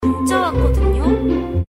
알림음 8_문자왔거든요.ogg